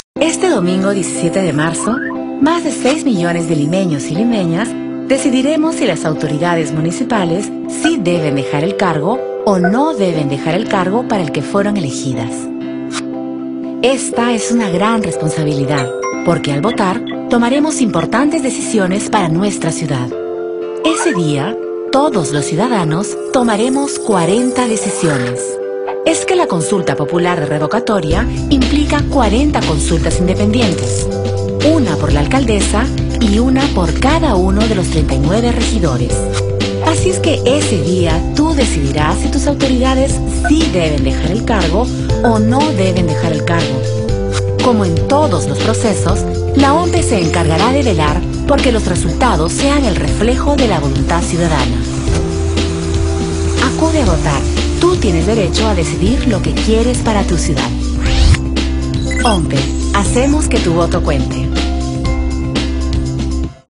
Espanhol - América Latina Neutro
ONPE - Voz Mulher Institucional
Voz Padrão - Grave 01:03